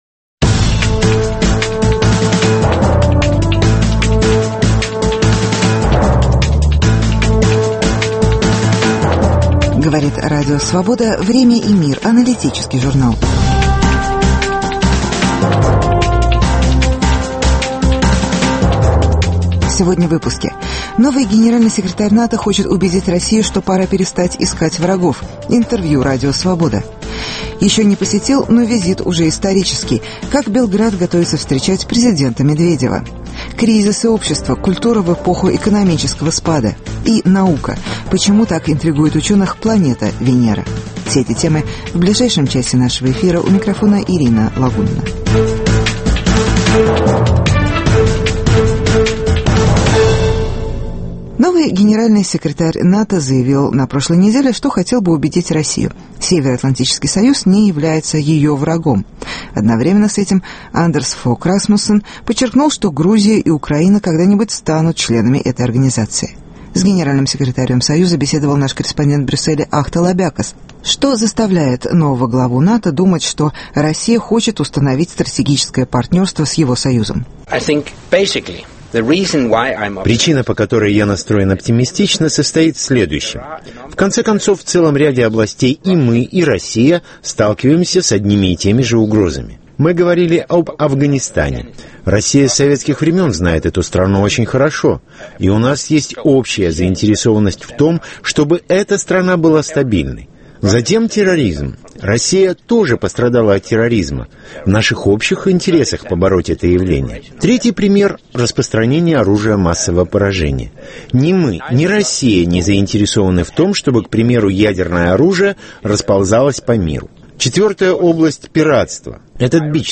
Интервью с Генеральным секретарем НАТО.